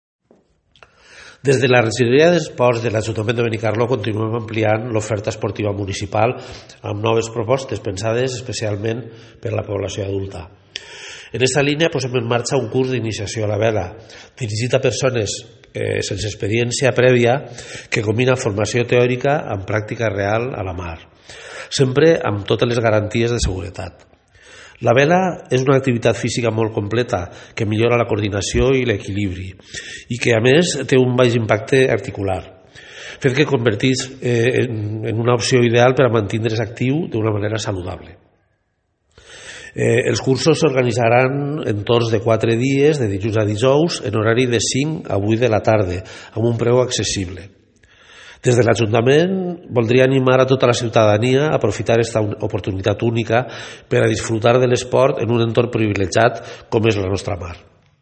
alcalde_vela.mp3